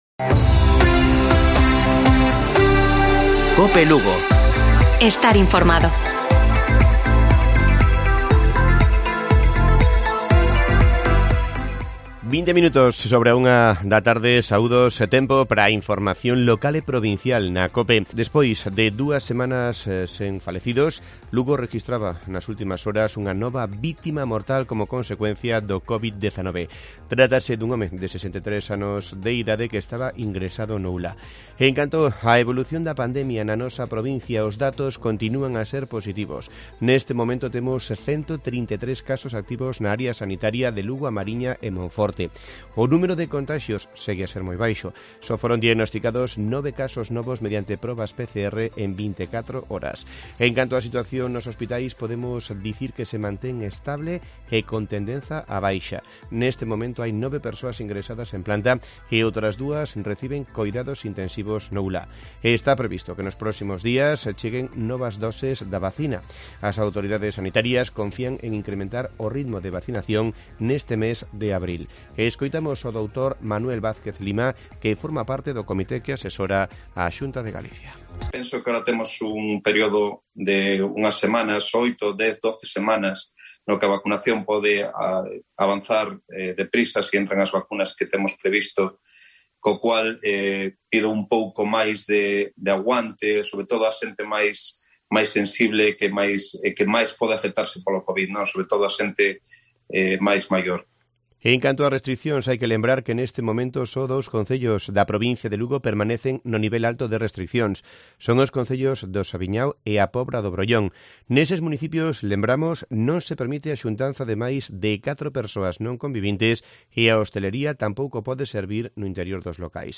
Informativo Provincial de Cope Lugo. 05 de abril. 13:20 horas